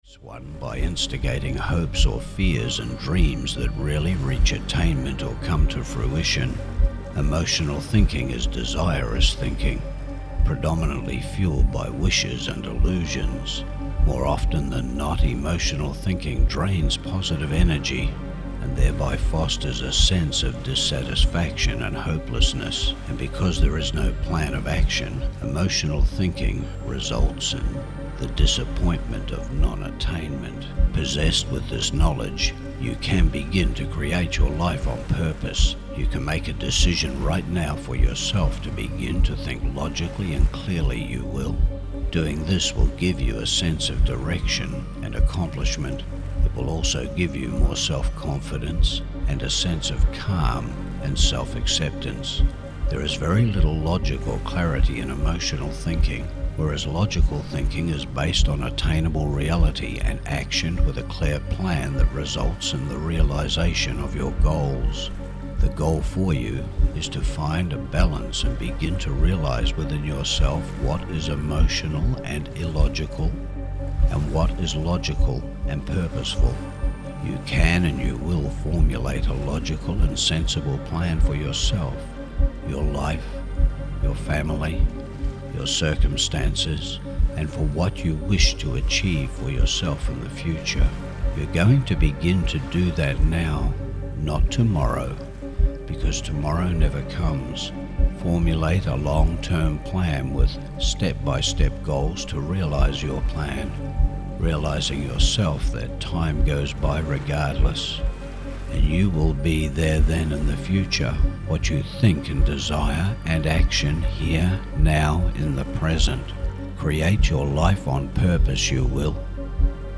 Self Appreciation & Respect Hypnosis | Mind Motivations